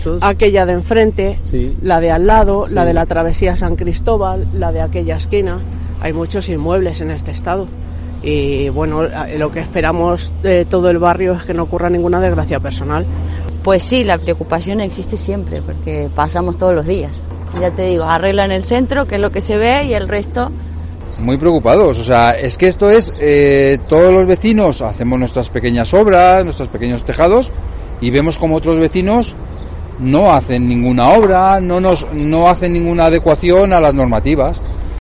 Los vecinos han advertido al micro de COPE que hay “hasta otras 8 viviendas en riesgo máximo de derrumbe”, aseguran que vivir en esta situación es “angustioso” porque, dicen, “un día puede pasar una desgracia”.